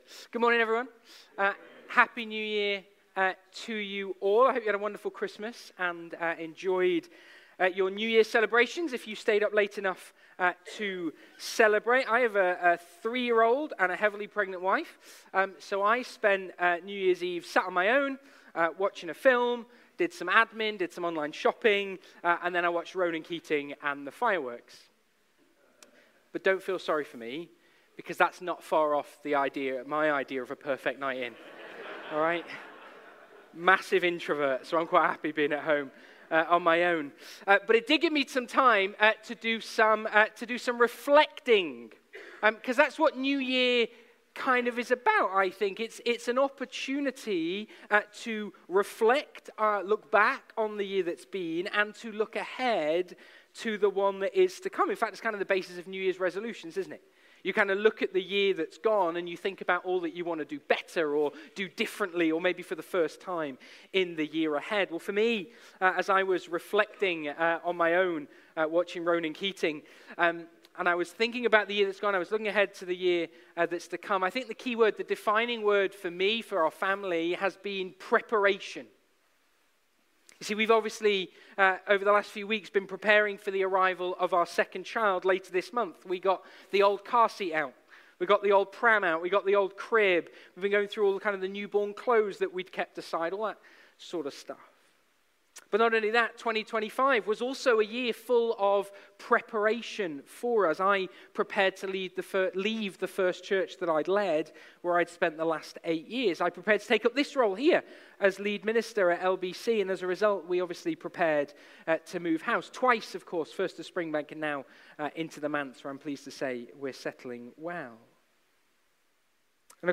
Passage: Numbers 1:47-54, Numbers 9:15-23 Service Type: Sunday Morning